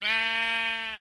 1.21.4 / assets / minecraft / sounds / mob / sheep / say3.ogg